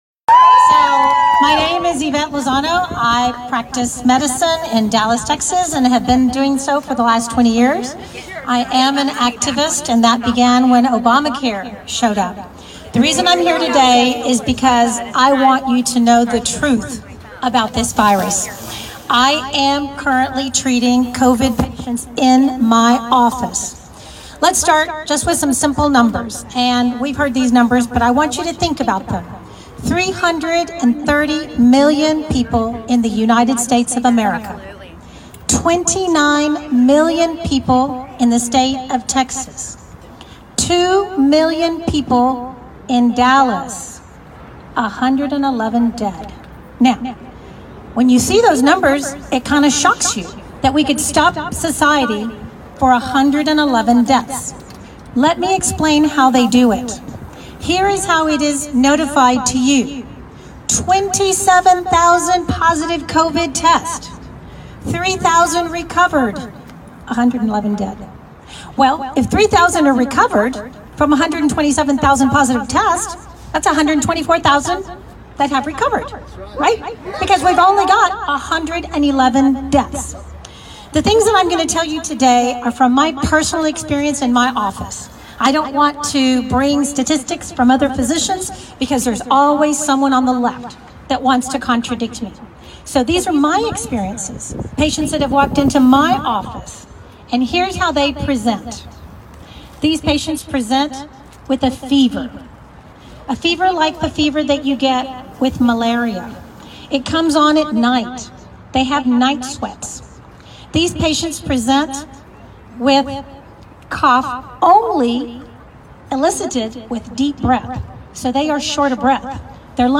Doctor at Texas Rally - hydroxychloroquine works and is being blocked. Powerful and short.